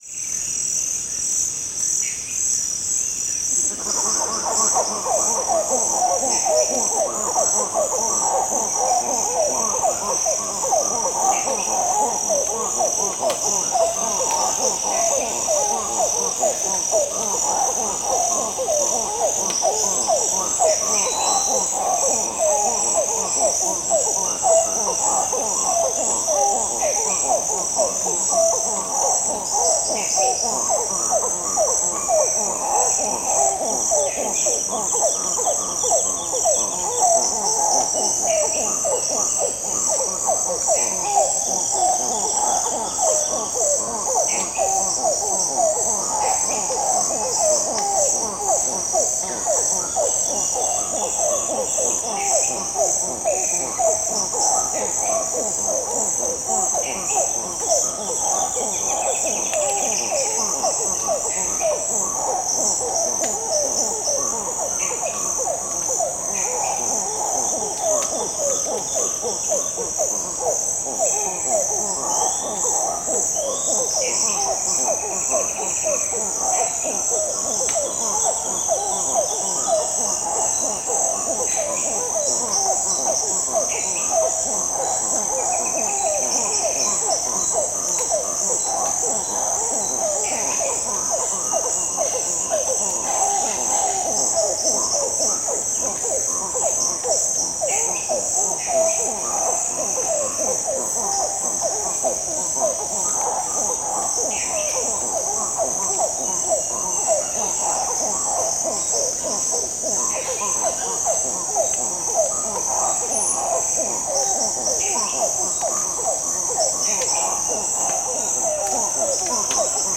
Zogzog monkey yelling in the Amazonian Rainforest
Category 🌿 Nature